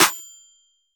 Snare (Atlanta).wav